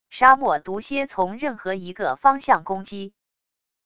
沙漠毒蝎从任何一个方向攻击wav音频